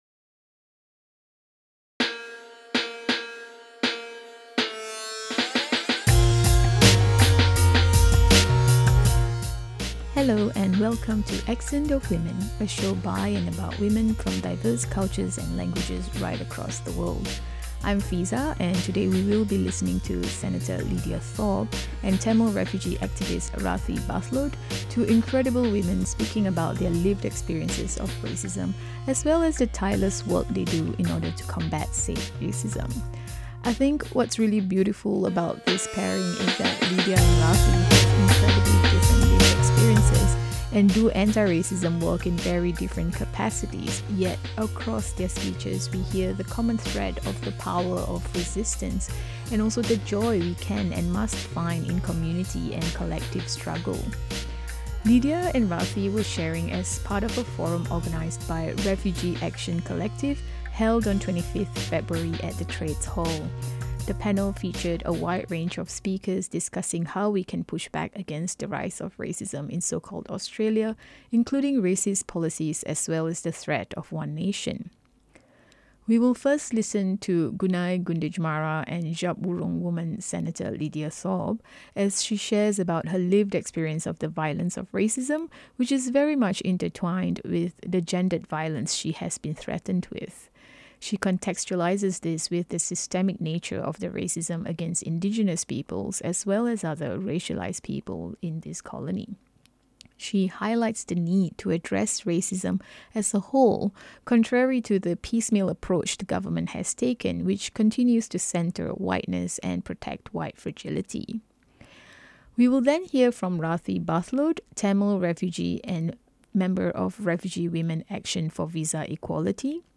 speaking at the 'Unite to Fight Racism Forum' organised by Refugee Action Collective held on 24 February 2026. Senator Lidia Thorpe shares about her own lived experience of racism, as well as the racism endured by her community. She highlights the need to address systemic racism systemically, rather than in piecemeal fashion such as the Special Envoys to Combat Antisemitism.